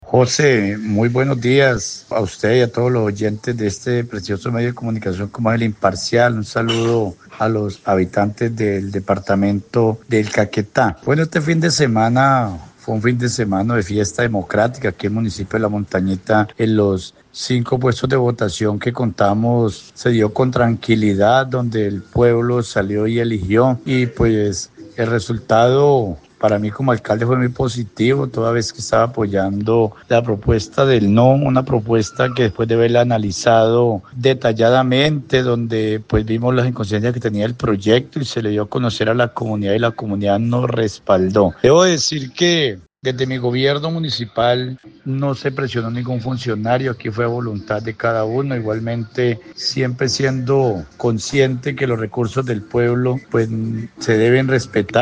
Federico Alviz, alcalde de la localidad, dice que, tras el resultado, continuará gestionando recursos para su municipio.
ALCALDE_FEDERICO_ALVIS_NO1_-_copia.mp3